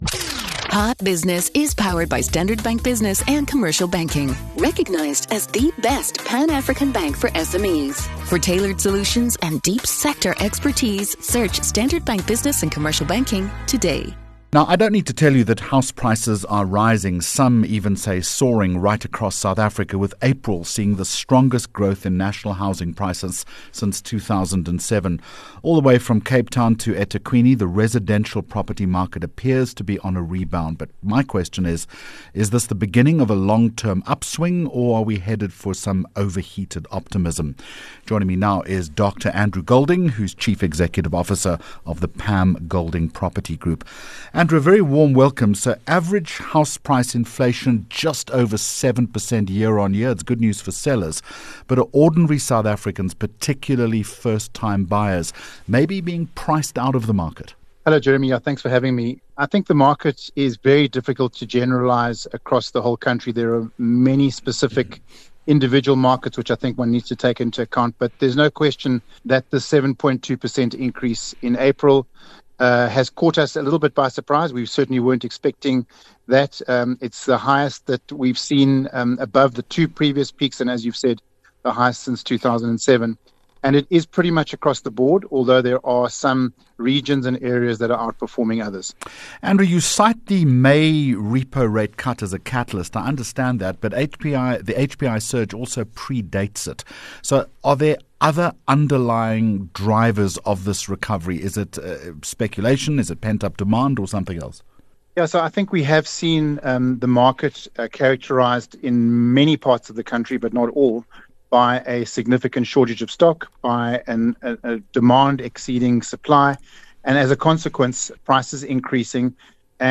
Hot Business Interview